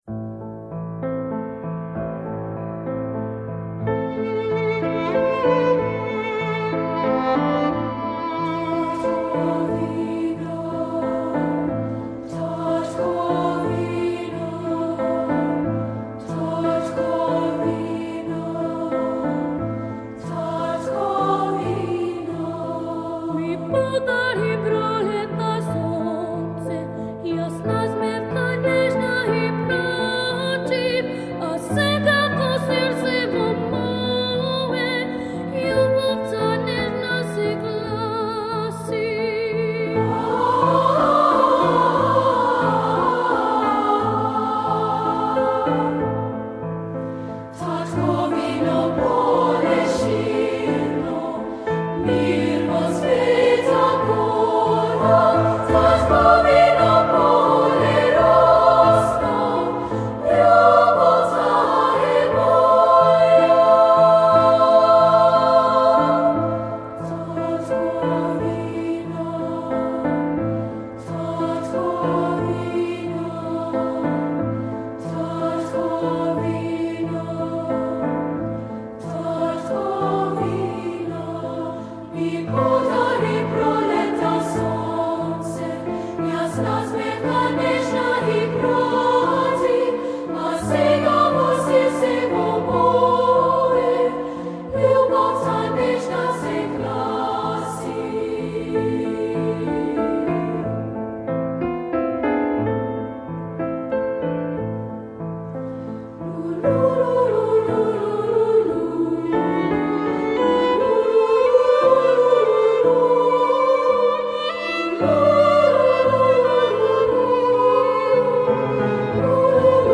Composer: Macedonian Folk Song
Voicing: 2-Part